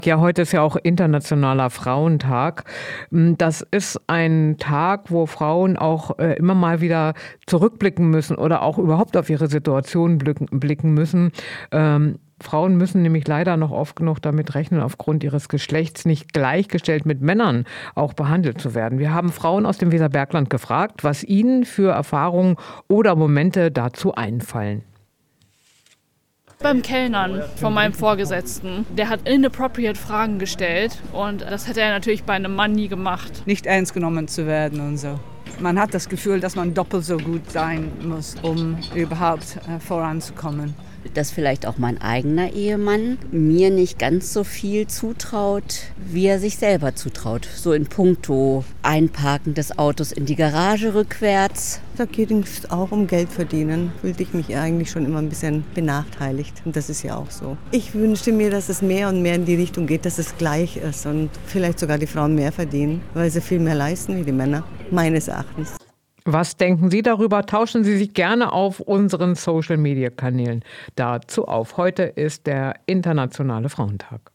Umfrage zum internationalen Frauentag
umfrage-zum-internationalen-frauentag.mp3